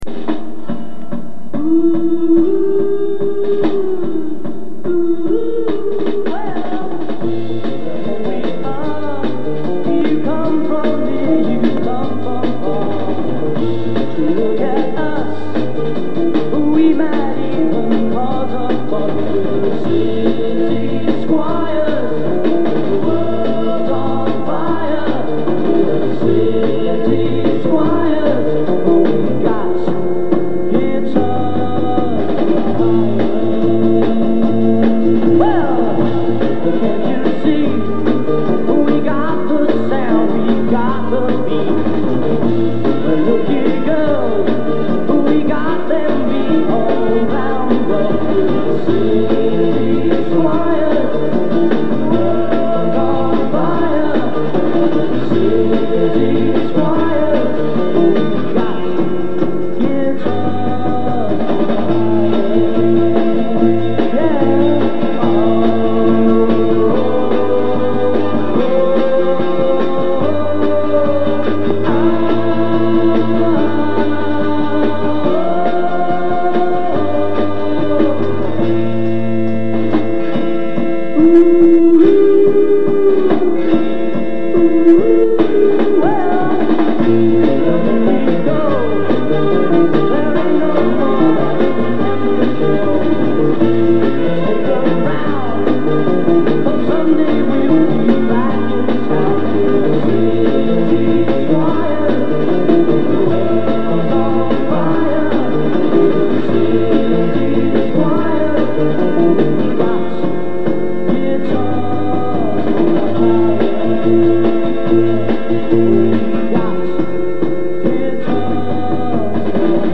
The song is a really catchy uptempo song